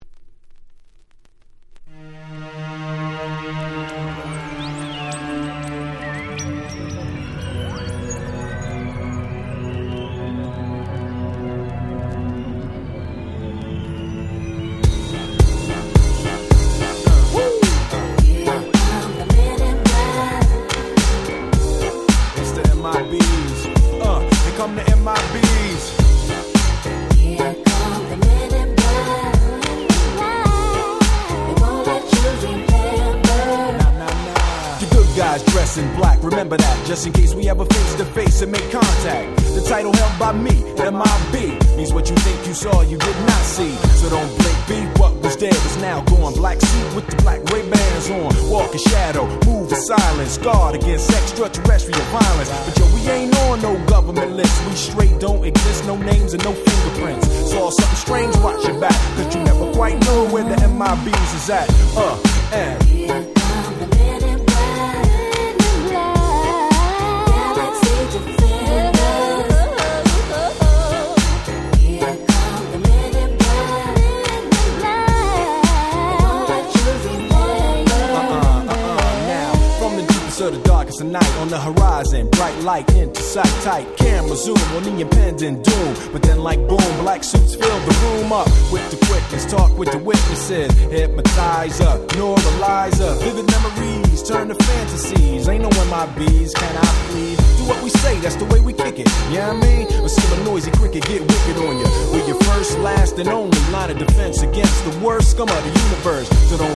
97' Super Hit Hip Hop !!